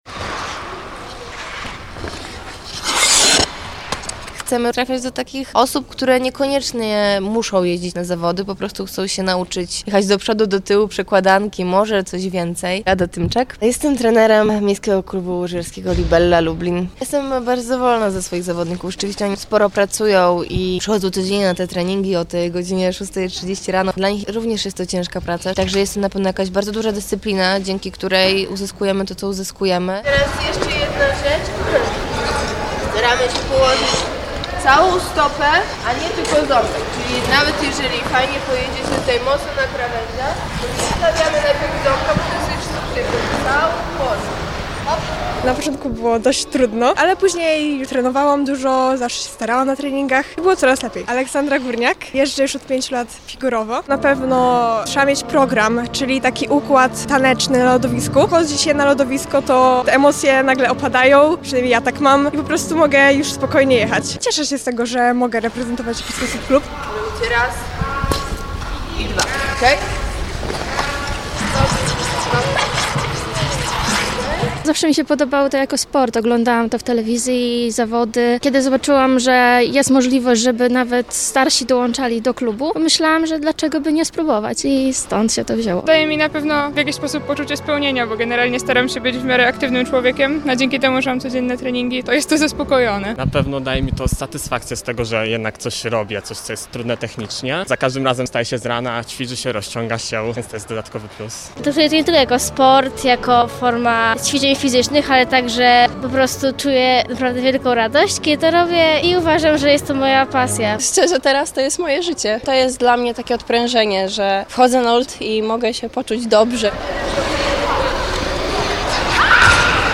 Inne zdanie na ten temat mają jednak zawodnicy MKŁ Libella Lublin. Na treningu pokazali próbkę swoich umiejętności, a przyglądali się im nasi reporterzy